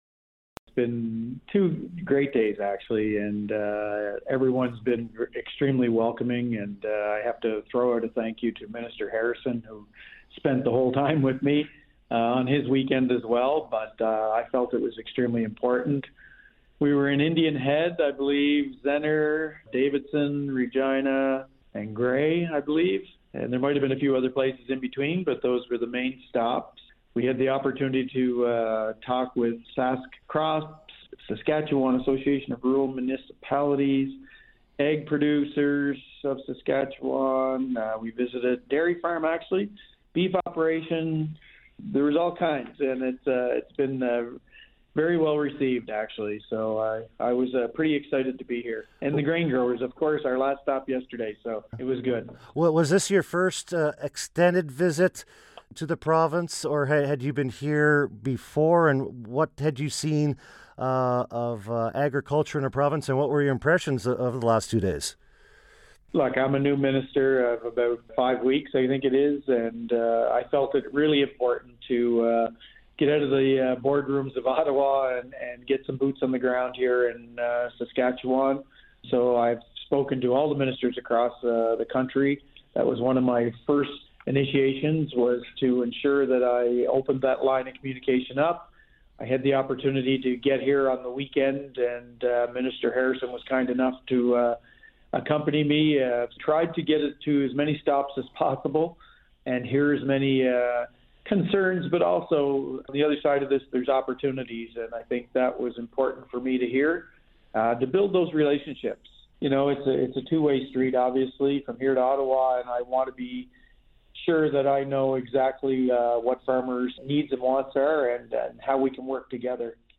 ag-minister-interview-june-15th.mp3